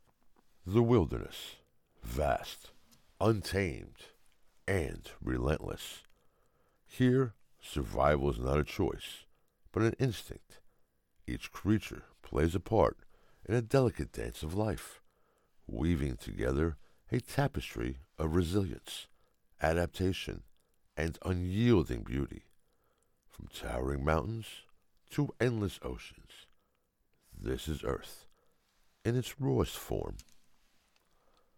Voice is Deep, Distinctive, and Authoritative, with a Commanding Presence that exudes Confidence and Power.
Nature Documentary Narration